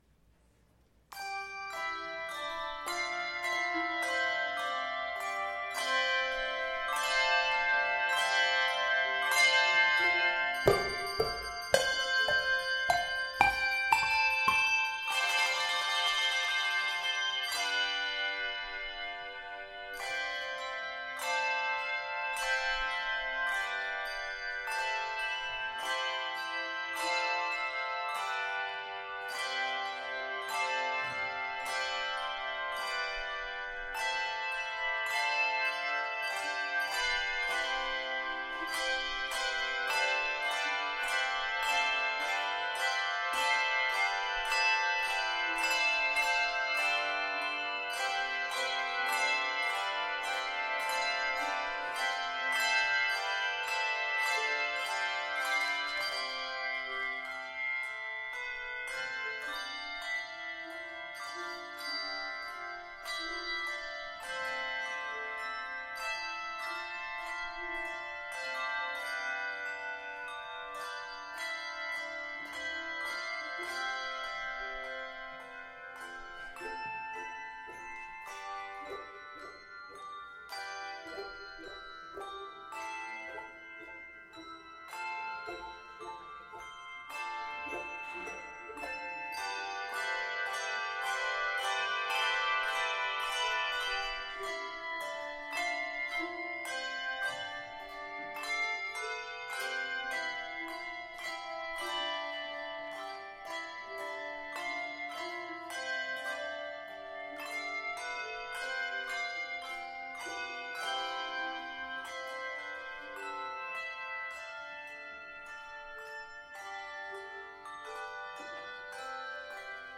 Music at the Sunday morning during the 10:00 worship service at Emmanuel is selected to reflect the day's scripture readings and/or the season of the Church Year.
March 6, 2016 Celebration of New Ministry Prelude
A Jubilant Peal performed by the Joyful Noise Handbell Choir